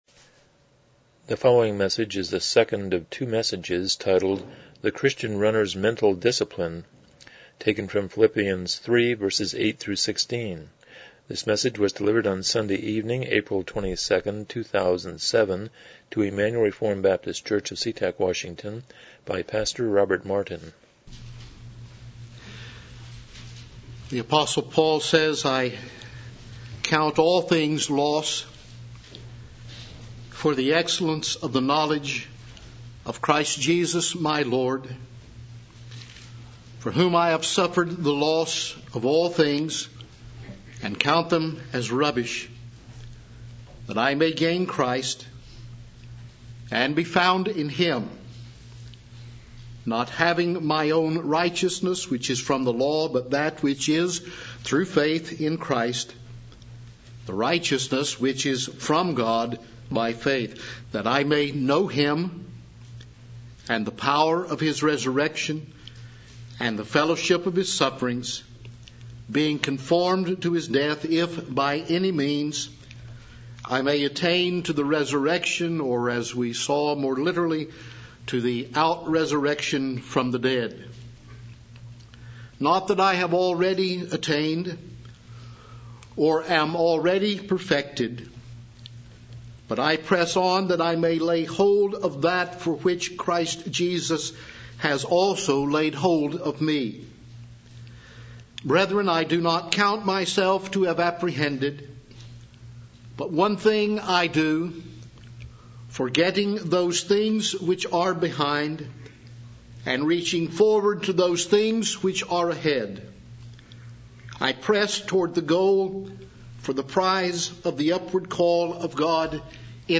Passage: Philippians 3:8-16 Service Type: Evening Worship